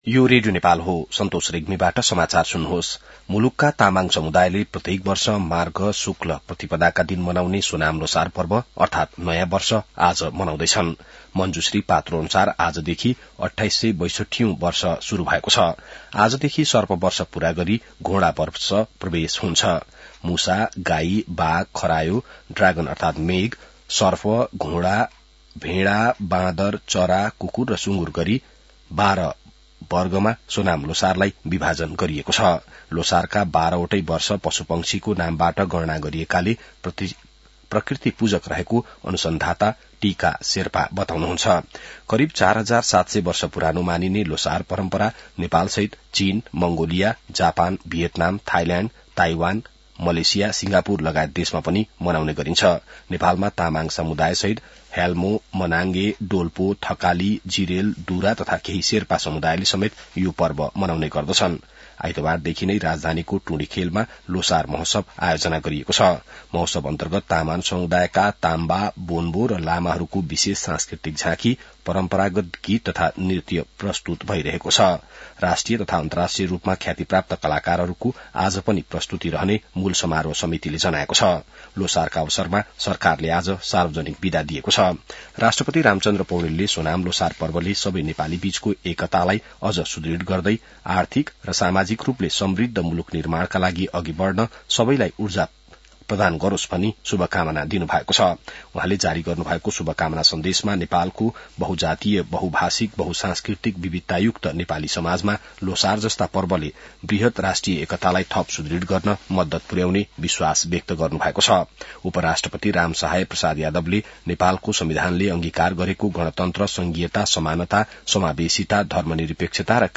An online outlet of Nepal's national radio broadcaster
बिहान ६ बजेको नेपाली समाचार : ५ माघ , २०८२